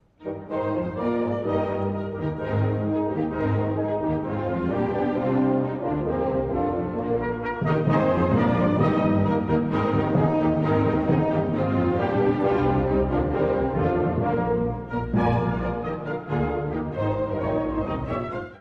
古い音源なので聴きづらいかもしれません！（以下同様）
第5楽章｜生き生きとした祝祭！
フィナーレは、生き生きとした第一主題から始まります。
行進曲風。全体として祝祭的です！
最後はより速くなり、Es-Durの和音が強調されて曲を終わりに導きます。